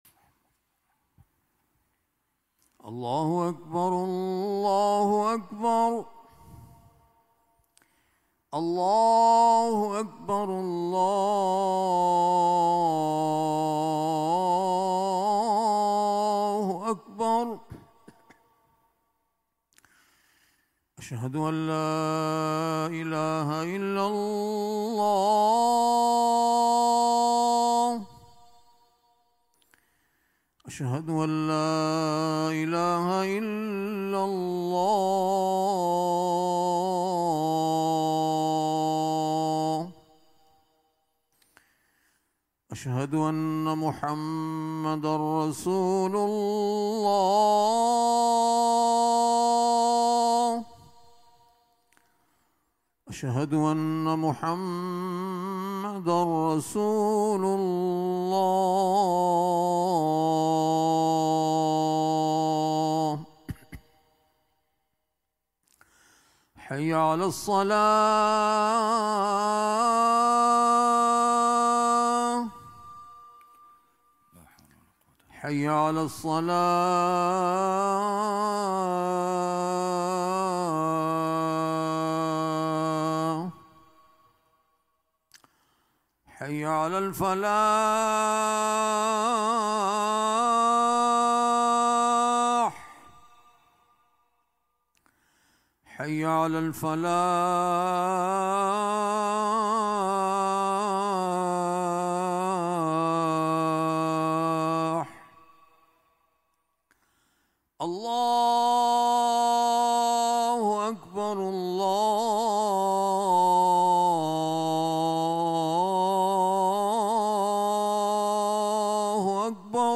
Friday Khutbah -"The Power of Words"